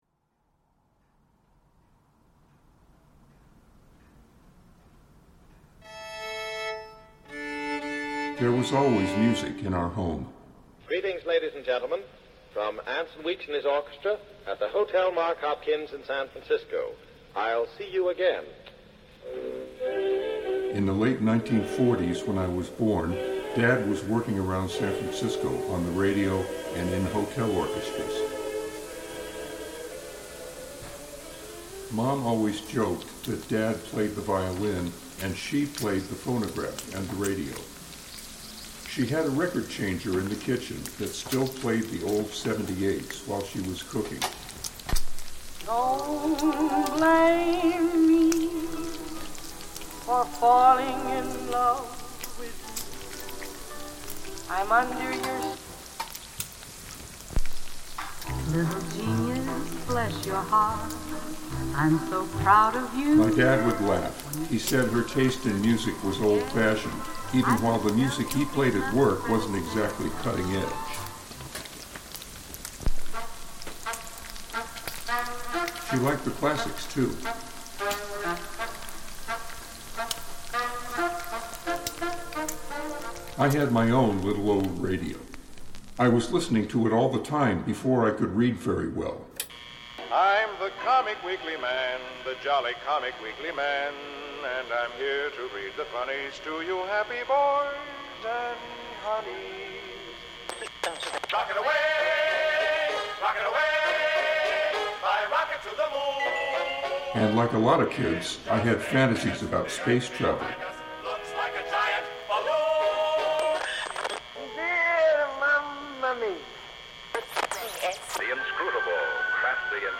documenting and reimagining the sounds of shortwave radio